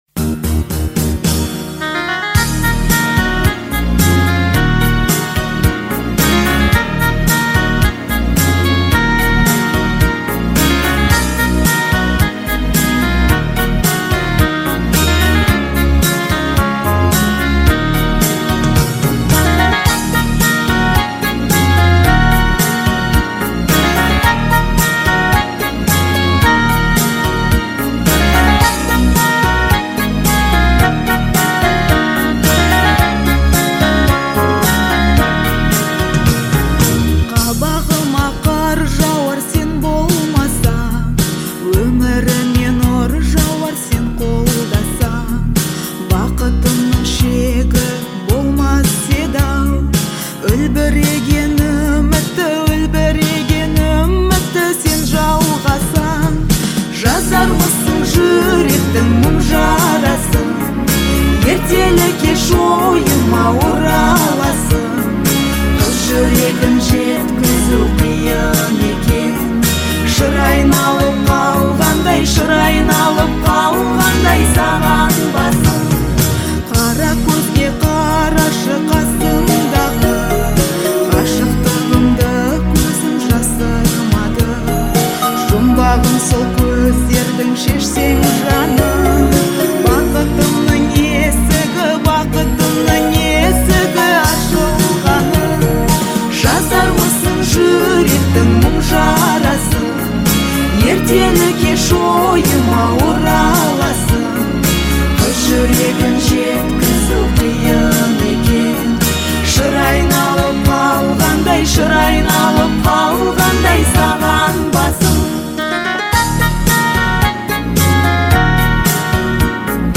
Звучание композиции отличается мелодичностью и гармонией